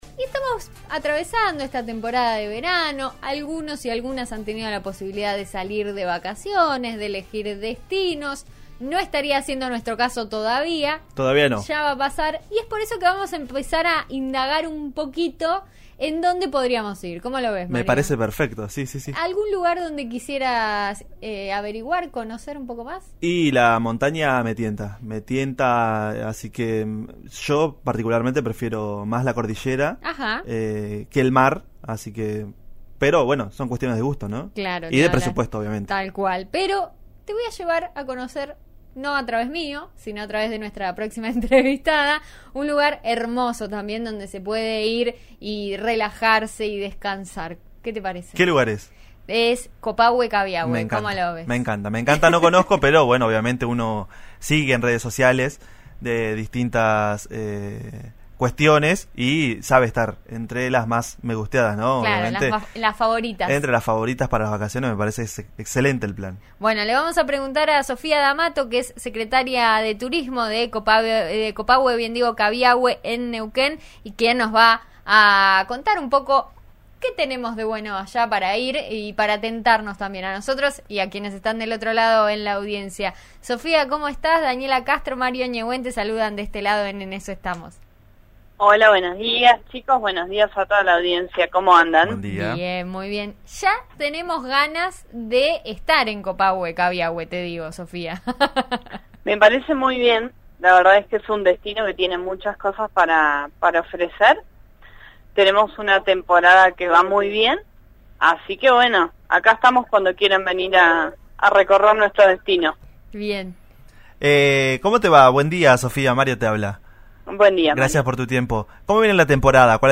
Sofía D´amato, secretaria de Turismo de Caviahue-Copahue, dio todos los detalles a RÍO NEGRO RADIO. Habló de los precios y las ofertas gastronómicas también.